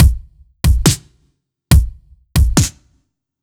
Index of /musicradar/french-house-chillout-samples/140bpm/Beats
FHC_BeatD_140-01_KickSnare.wav